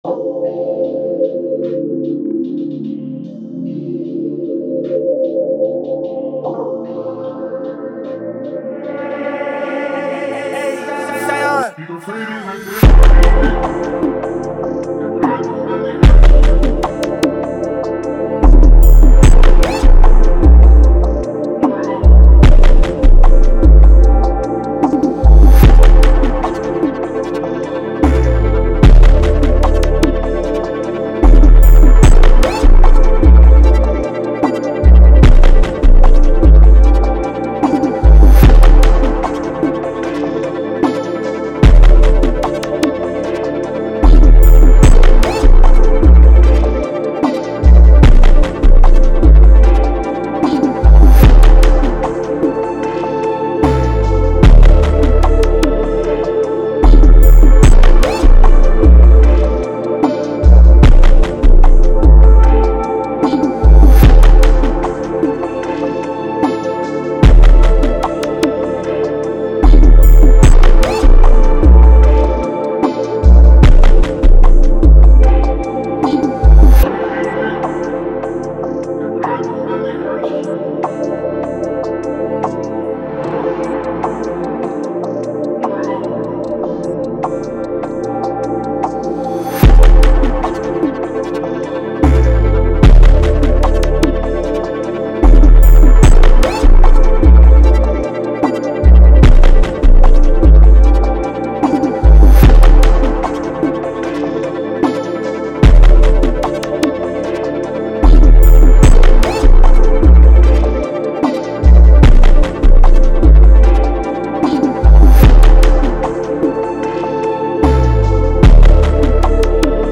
150 D Minor